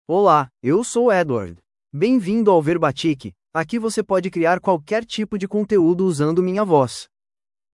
MalePortuguese (Brazil)
EdwardMale Portuguese AI voice
Voice sample
Listen to Edward's male Portuguese voice.
Male